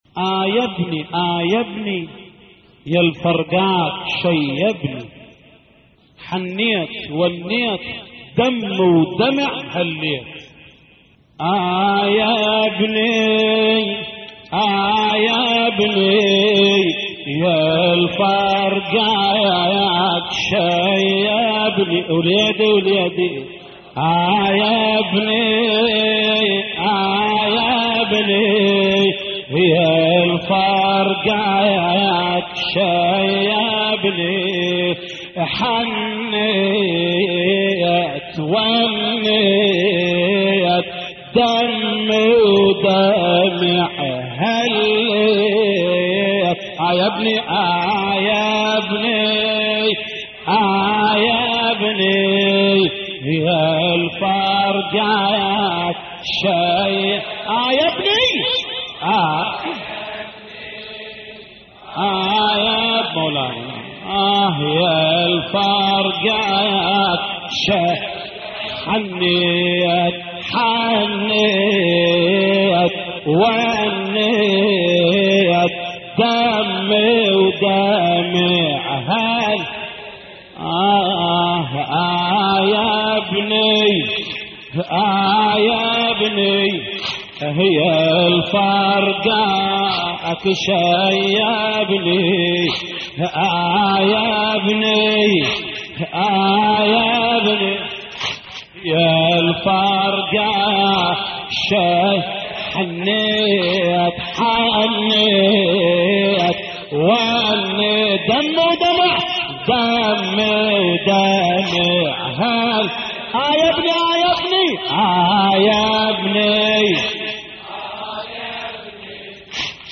تحميل : اه يا ابني يالفرقاك شيبني حنّيت ونّيت / الرادود جليل الكربلائي / اللطميات الحسينية / موقع يا حسين